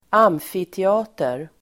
Uttal: [²'am:fitea:ter]
amfiteater.mp3